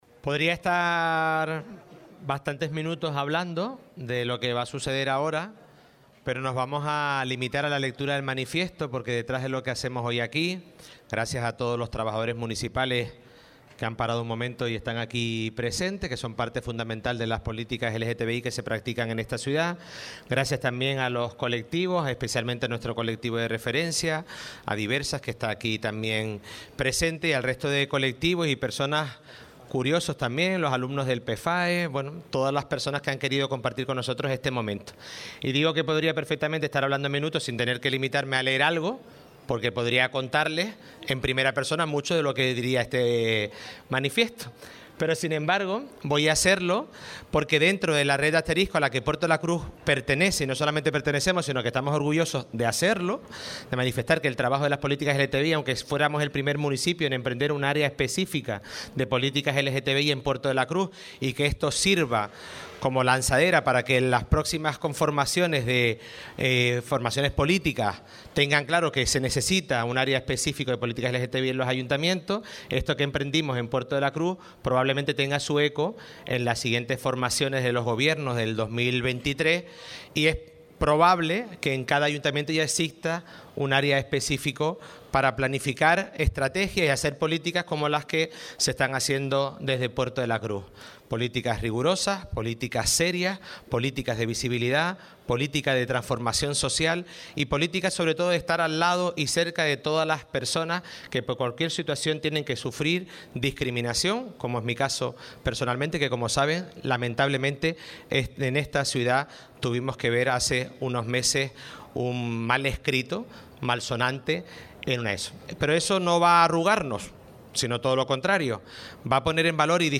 Puerto de la Cruz. Lectura manifiesto LGTBI e iza la bandera arco iris en la Plaza de Europa.
puerto-de-la-cruz-lectura-manifiesto-lgtbi-e-iza-la-bandera-arco-iris-en-la-plaza-de-europa.mp3